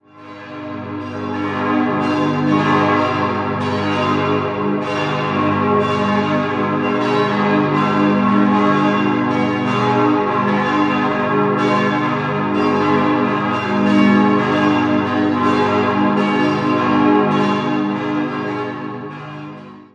Im Jahr 1904 wurde die reformierte Kirche, ein Zentralbau, eingeweiht. 5-stimmiges As-Dur-Geläute: as°-c'-es'-as'-c'' Die Glocken wurden im Jahr 1903 von der Gießerei Rüetschi in Aarau hergestellt.